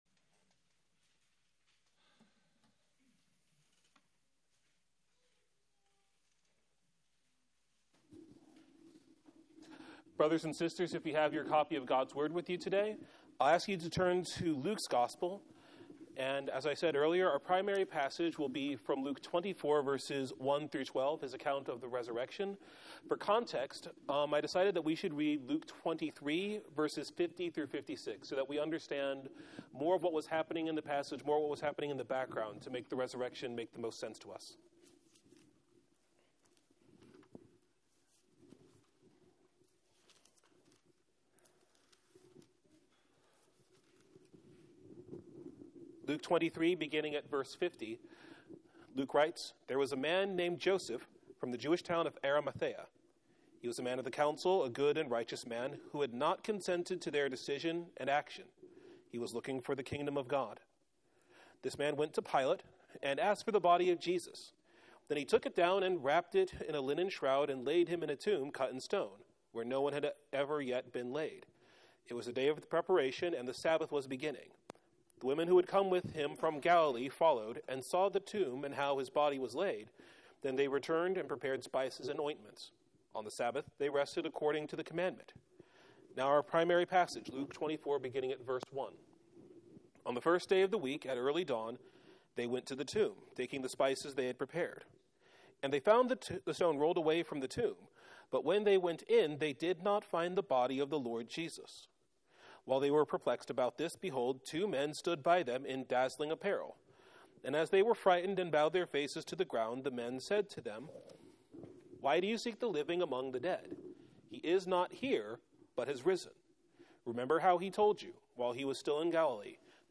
The reality of the empty tomb The reason for the empty tomb The reaction to the empty tomb Series: Easter Sermons